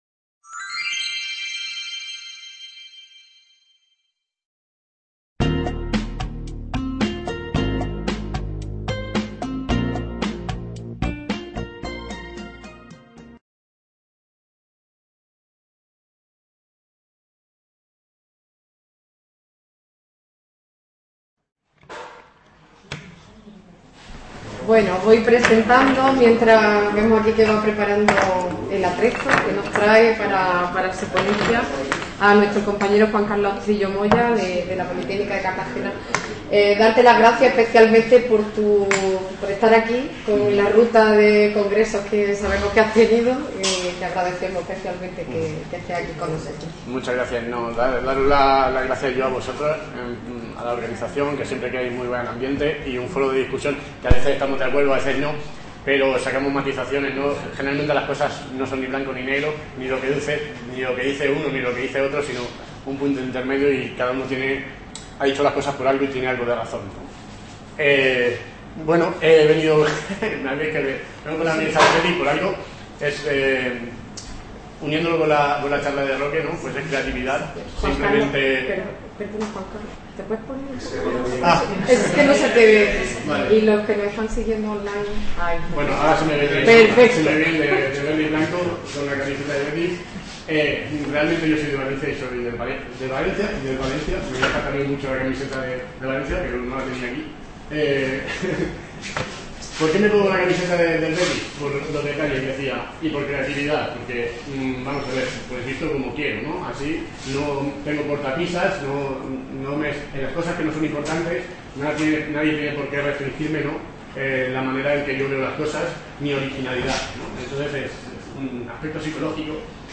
IV Jornadas de Experiencias e Innovación Docente en Estadística y Matemáticas (EXIDO)
Authorship & License License Rights BY-NC-SA Público Academic Information Room Aula Virtual del Centro Asociado de Jaén Attached Resources Attached Resources Movil Audio Video